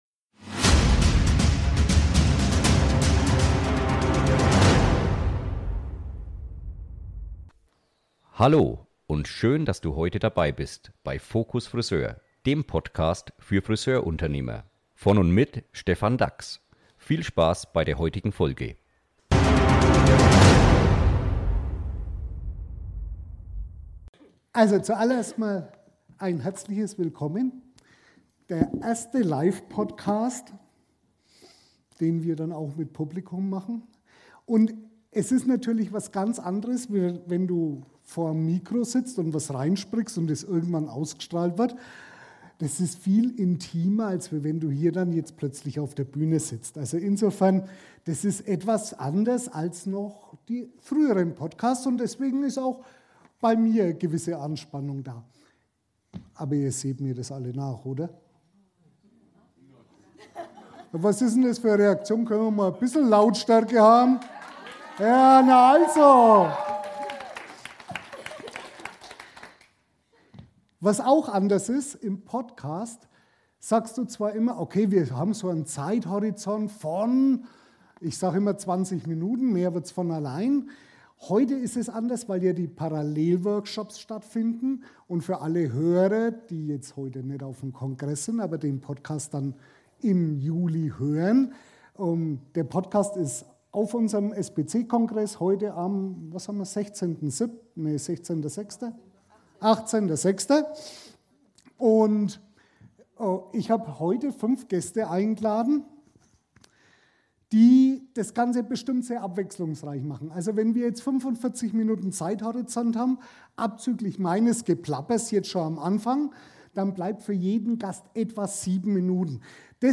Live Podcast vom SPC - Kongress ~ Focus Friseur Podcast